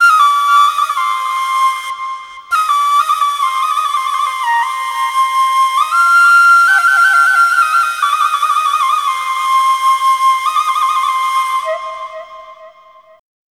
EASTFLUTE2-L.wav